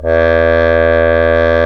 Index of /90_sSampleCDs/Roland LCDP12 Solo Brass/BRS_Trombone/BRS_TromboneMute